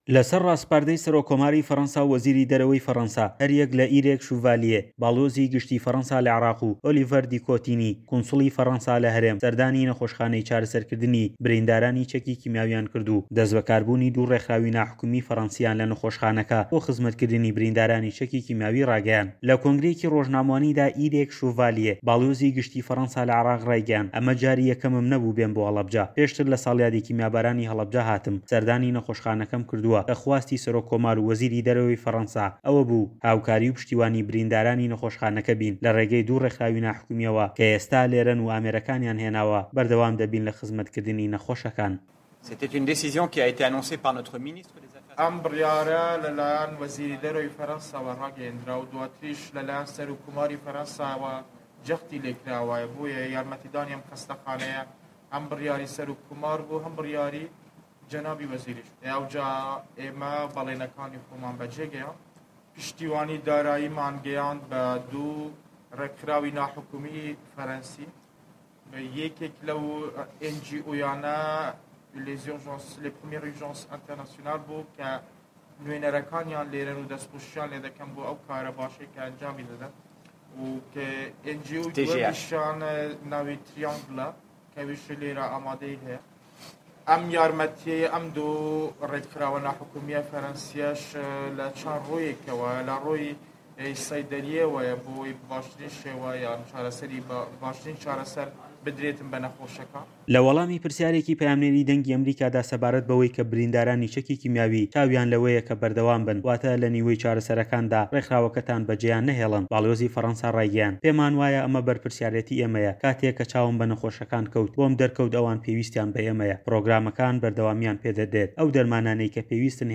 لە کۆنگرەیەکی ڕۆژنامەوانیدا ئێریک شوڤالییێ باڵیۆزی گشتی فەرەنسا لە عێراق ڕایگەیاند" ئەمە جاری یەکەمم نەبوو سەردانی هەڵەبجە بکەم، پێشتر لە ساڵیادی کیمیابارانی هەڵەبجە سەردانی ئەم نەخۆشخانەیەم کرد کە خواستی سەرۆککۆمار و وەزیری دەرەوەی فەڕەنسا ئەوە بوو هاوکار و پشتیوانی بریندارانی نەخۆشخانەکە بین، لە ڕێگەی دوو ڕێکخراوی ناحکومیەوە، کە ئێستا لێرەن و ئامێرەکانیان هێناوە بەردەوامین لە خزمەتکردنی نەخۆشەکان."